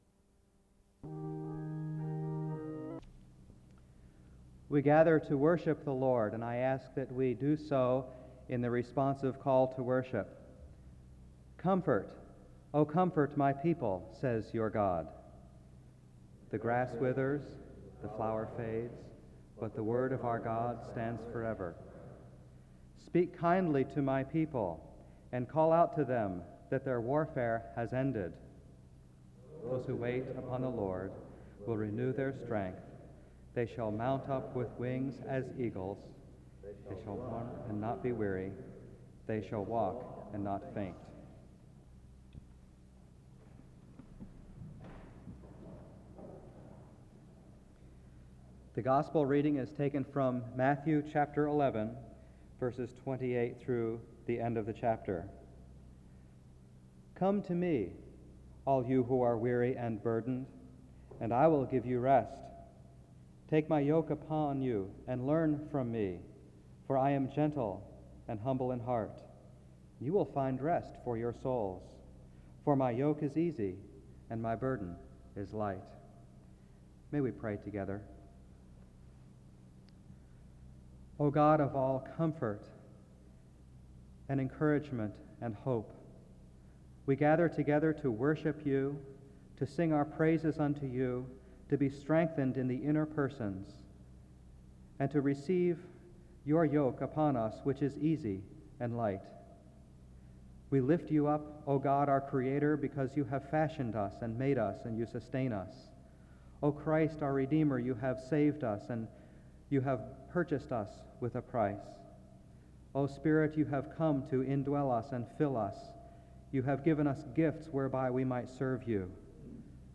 The audio was transferred from audio cassette. The service begins with responsive reading, prayer, and the scripture reading on 1 Corinthians 3:1-9 from 0:04-3:39.